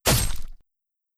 Melee Weapon Attack 13.wav